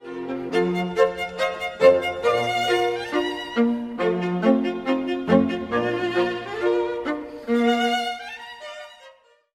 mp3Bologne, Joseph, String Quartet No. 3, mvt. I. Allegro Assai, mm.15-18